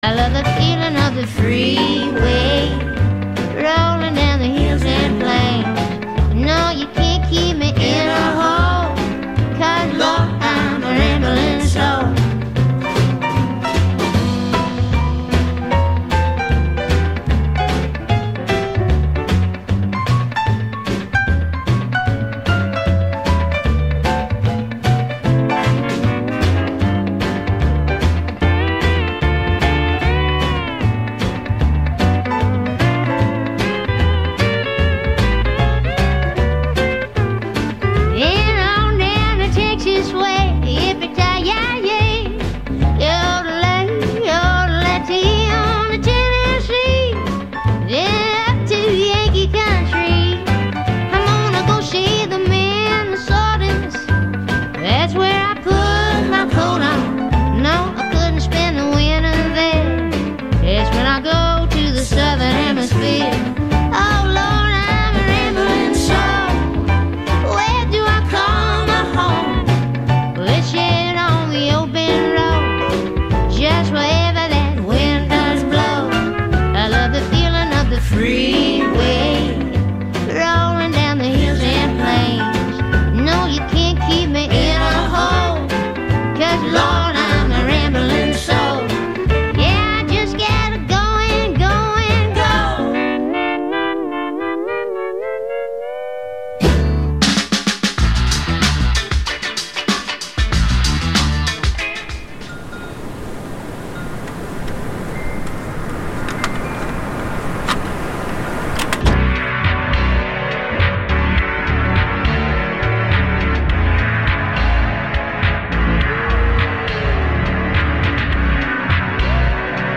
Big Picture Science is a weekly, one-hour radio show and podcast produced at the SETI Institute.
Themed episodes feature interviews with top scientists and technology innovators.